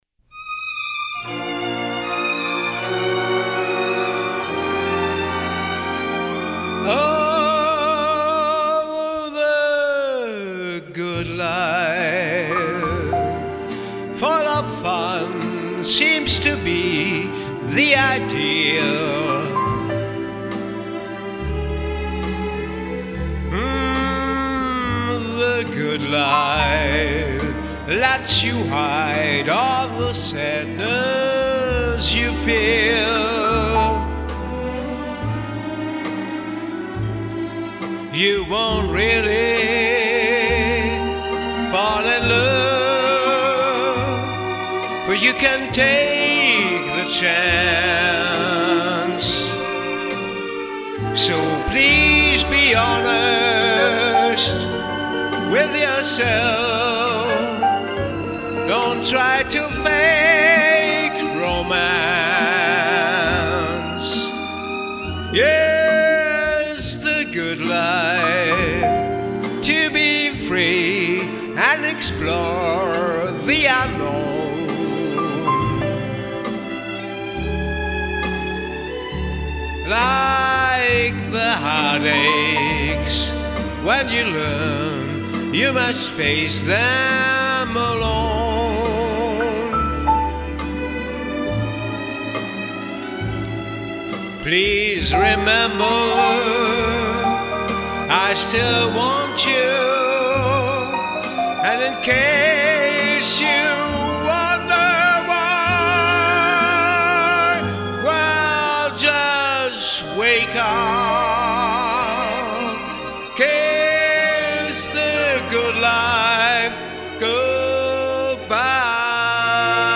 Style: Classics